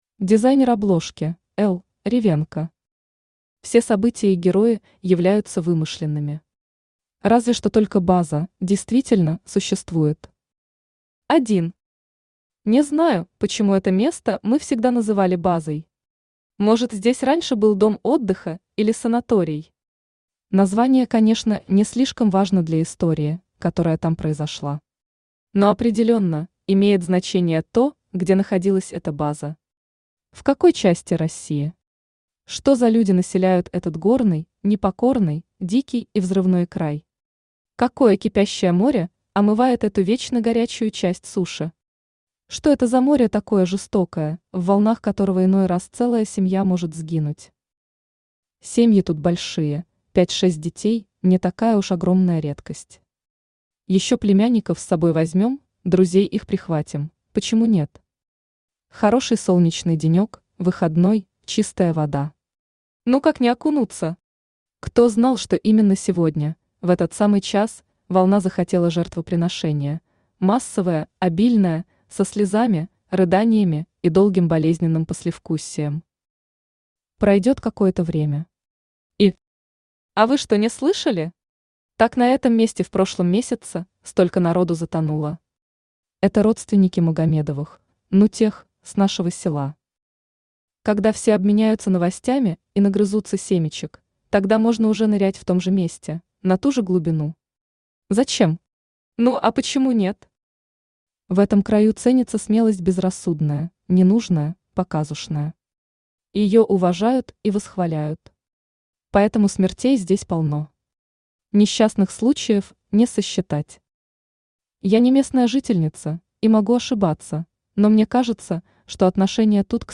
Aудиокнига База для убийства Автор Е. Ермак Читает аудиокнигу Авточтец ЛитРес.